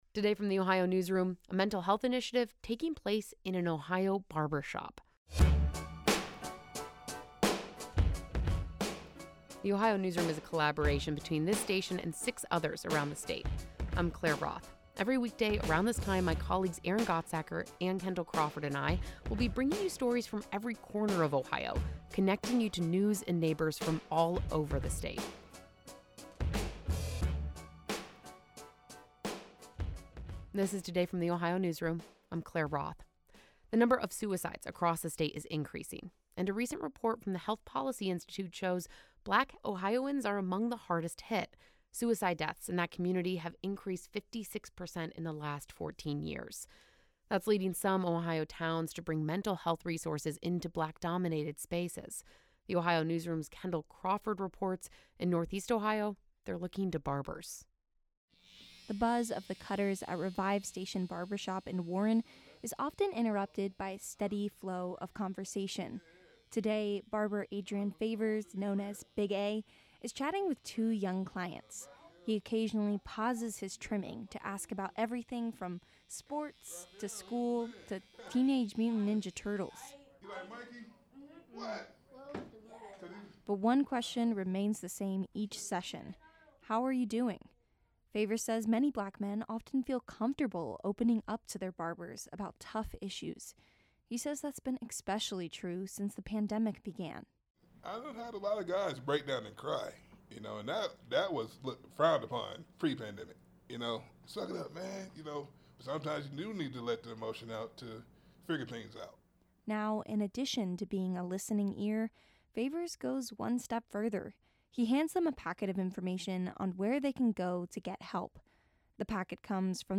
The buzz of the cutters at Revive Station Barbershop in Warren often overlaps with a steady flow of conversation.